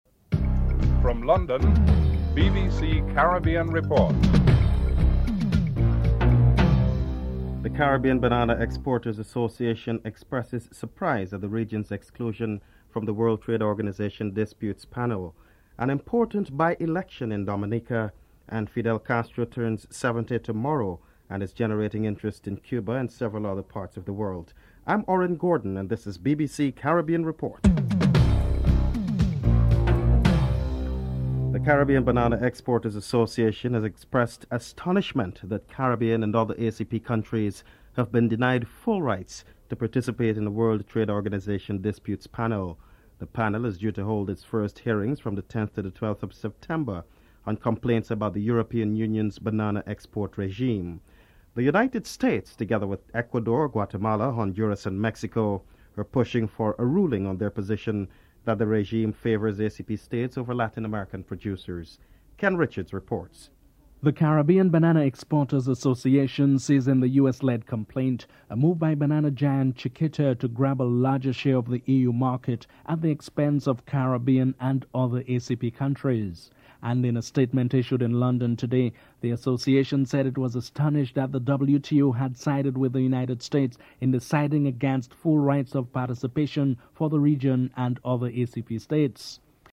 1. Headlines (00:00-00:31)
Just how popular is Fidel Castro today? A friend of Fidel Castro is interviewed.